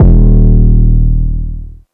808s
REDD 808 (1).wav